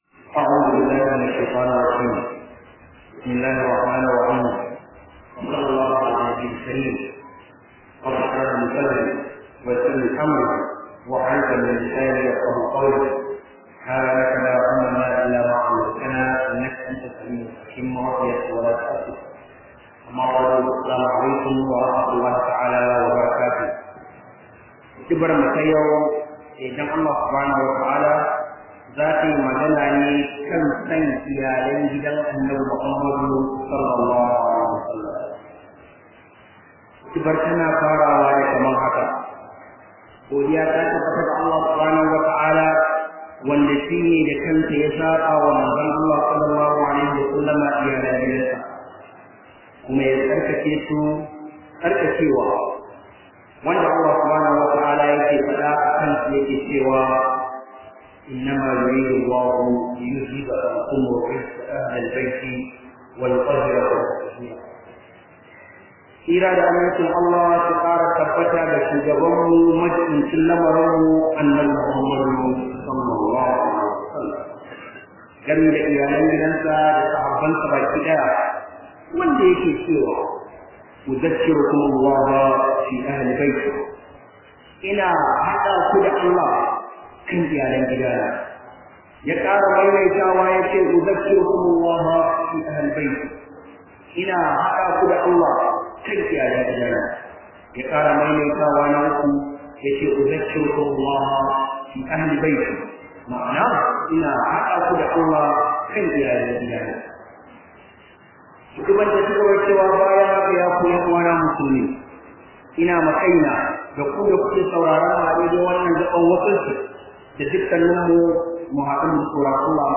KHUDBAH JUMA-A